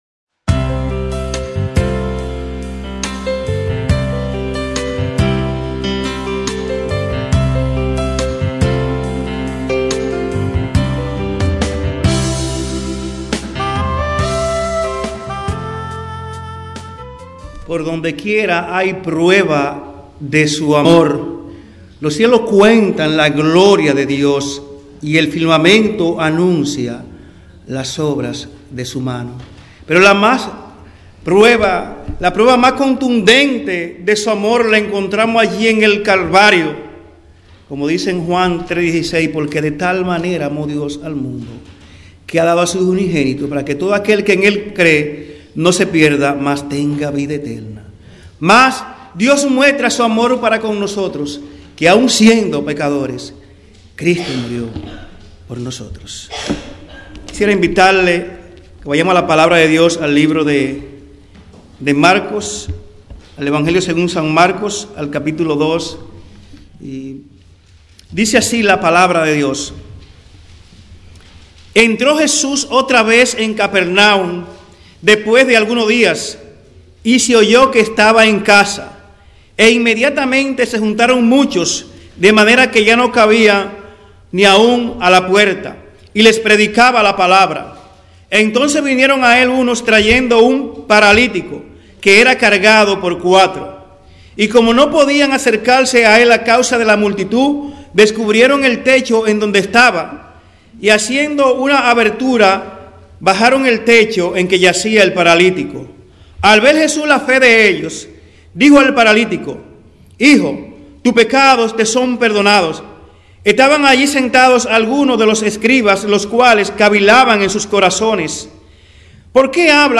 Descargar Aquí Templo Bíblico Providence Expositor